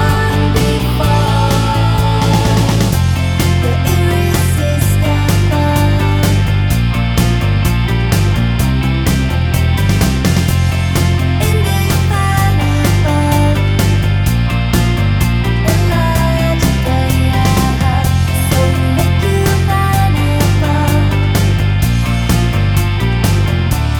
No Backing Vocals Irish 3:38 Buy £1.50